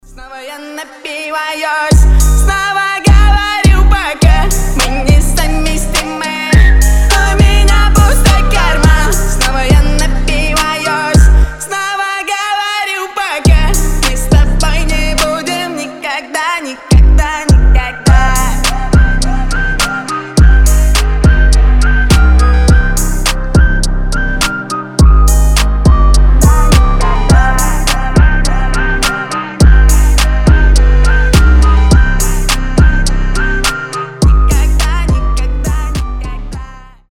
Рэп рингтоны
Басы , Свист